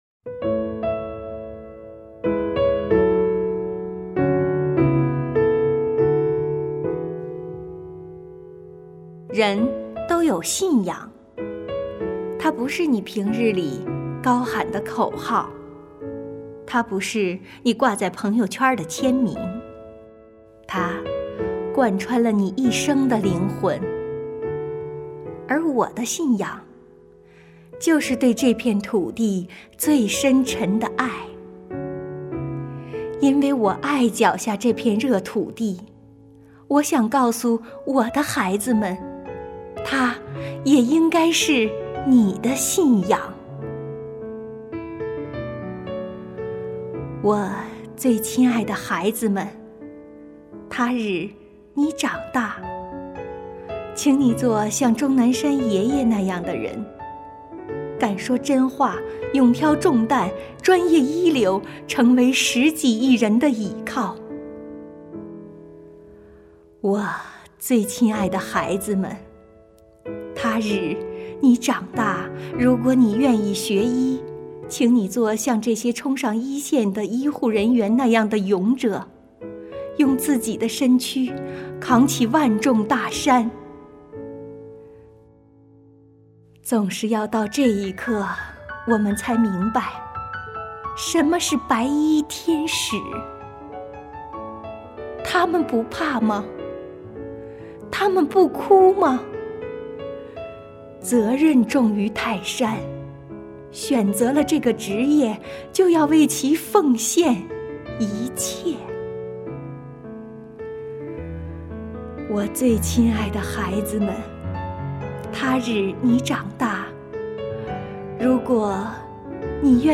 诗朗诵《给孩子们的一封信》抗疫路上，为你记录——黑龙江文艺界“战疫”优秀文艺作品展播